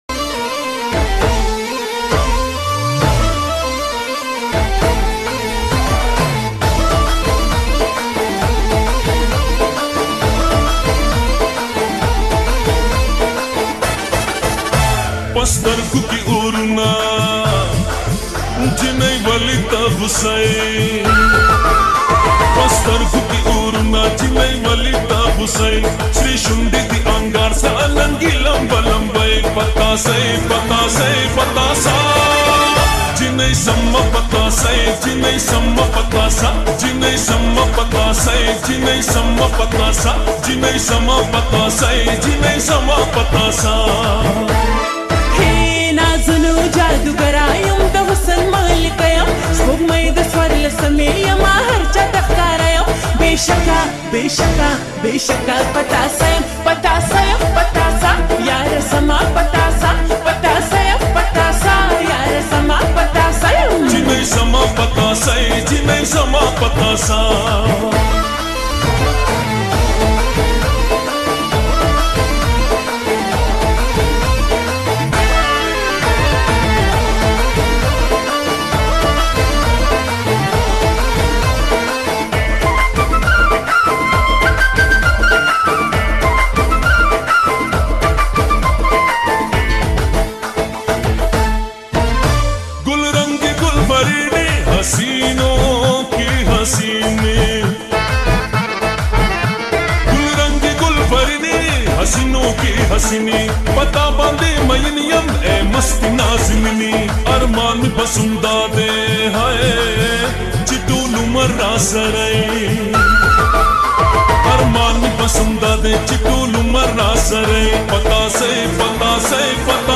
Pushto trending song